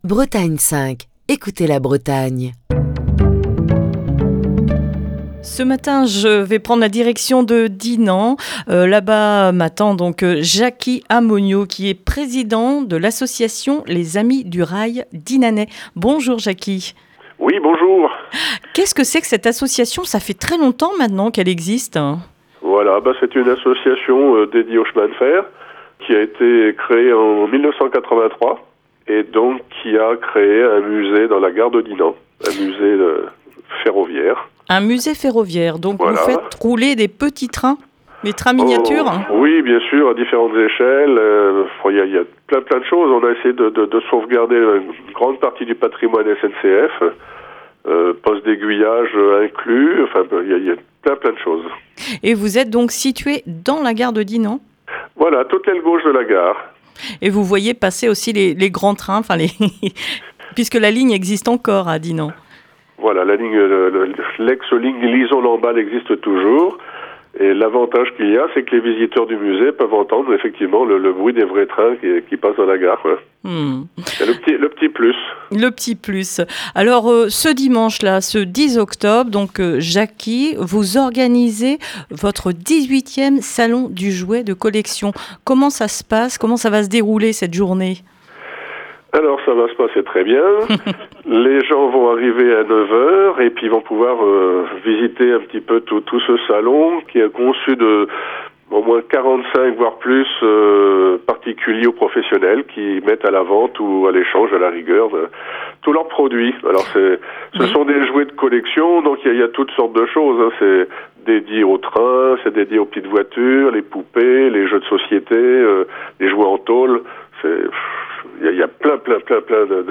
Ce vendredi dans le coup de fil du matin